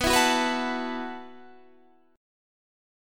B7sus4 chord